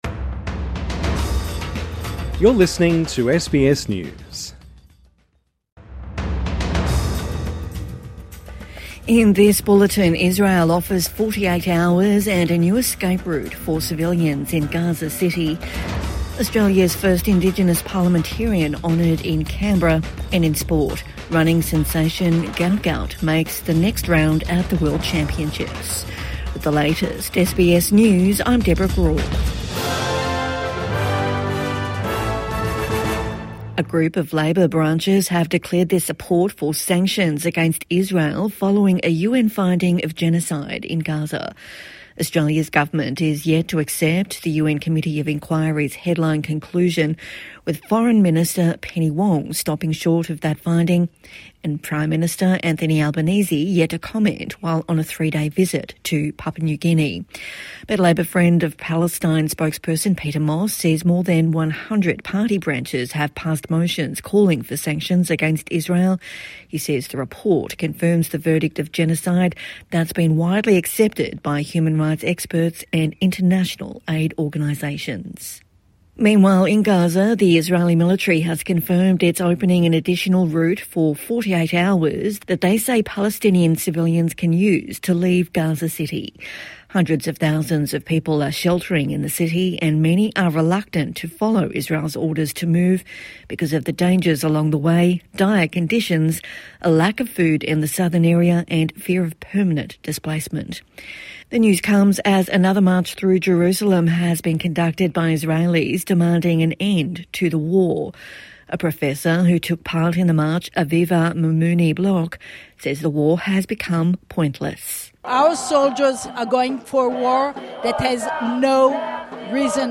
New exit route opened in Gaza city | Morning News Bulletin 18 September 2025